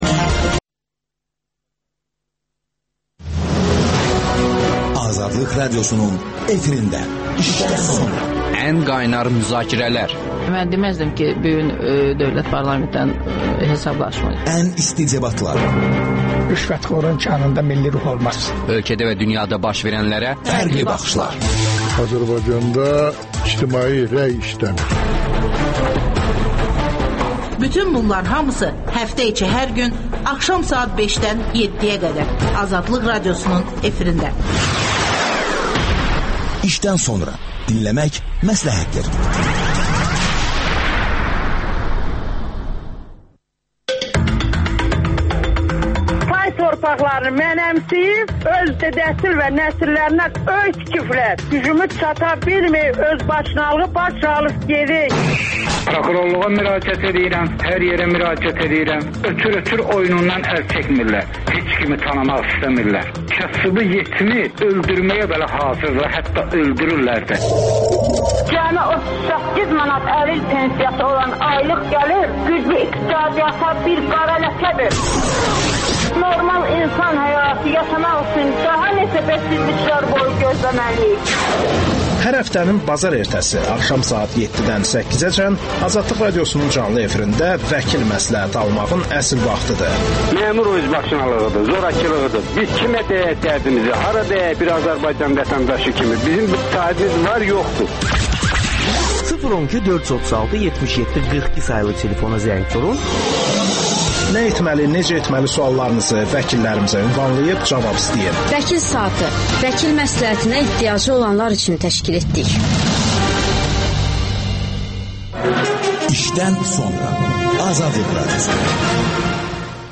İşdən sonra - Cəmil Həsənli canlı efirdə